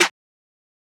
21 Snare.wav